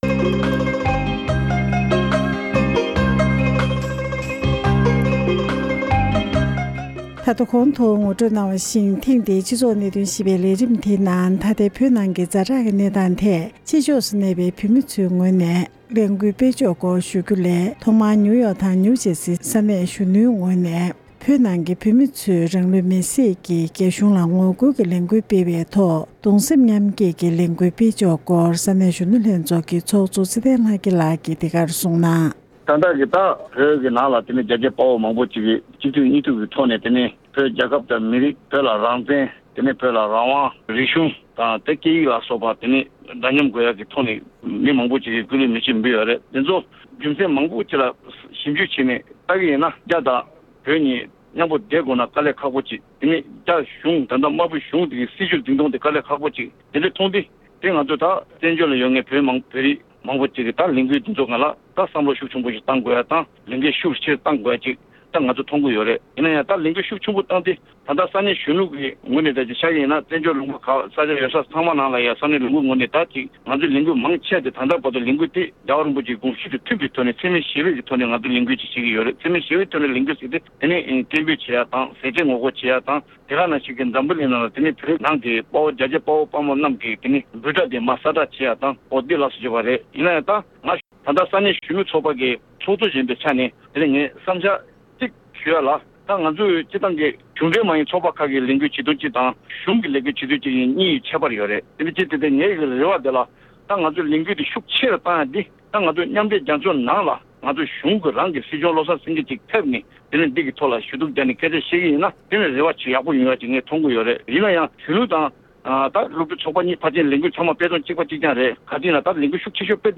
བོད་ནང་གི་རང་ལུས་མེ་སྲེག་ཐད་ཕྱི་ཕྱོགས་སུ་གདུང་སེམས་མཉམ་བསྐྱེད་ཀྱི་ལས་འགུལ་སྤེལ་ཕྱོགས་ཐད་བཅར་འདྲི།